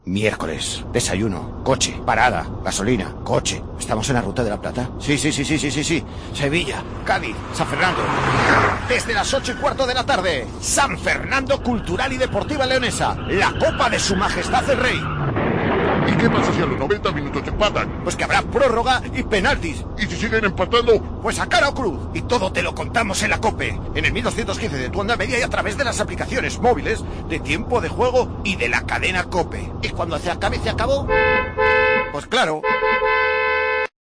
Escucha la cuña promocional del partido San Fernando - Cultural el día 01-12-21 a las 20:30 h en el 1.215 OM